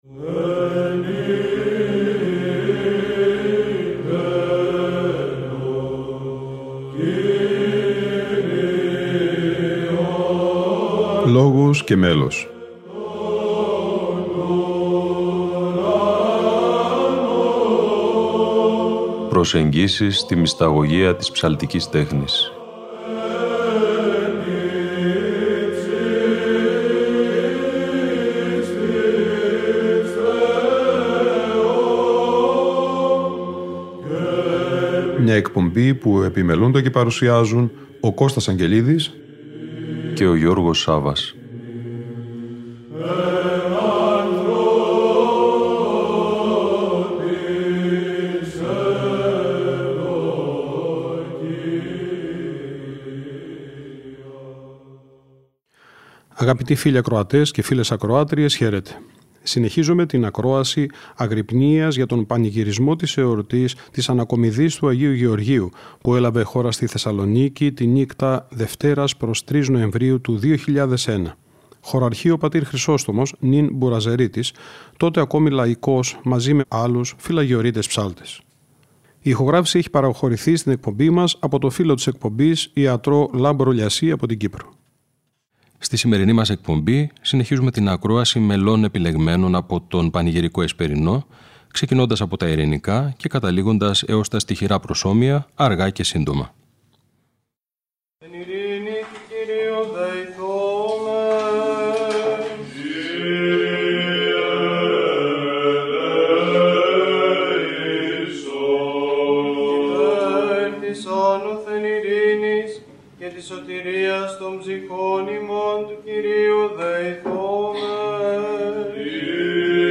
Αγρυπνία Ανακομιδής Λειψάνων Αγίου Γεωργίου - Ροτόντα 2001 (Β΄)